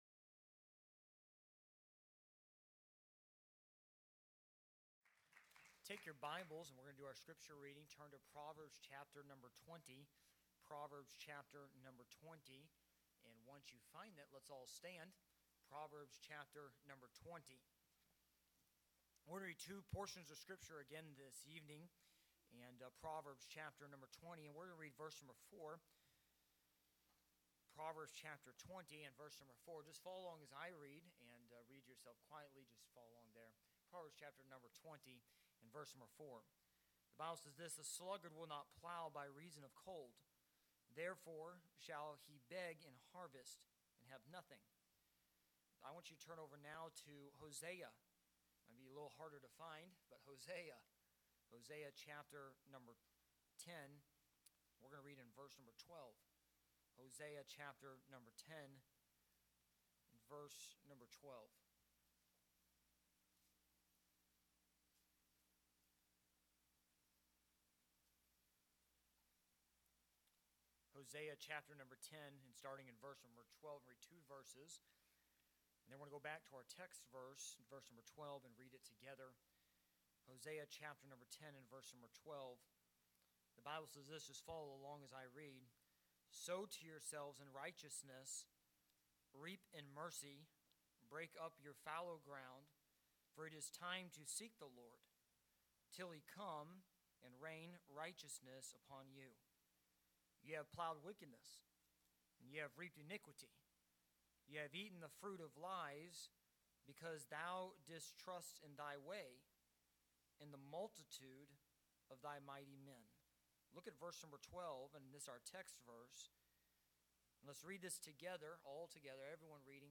Sermons preached from the pulpit of Anchor Baptist Church in Columbus, Ohio.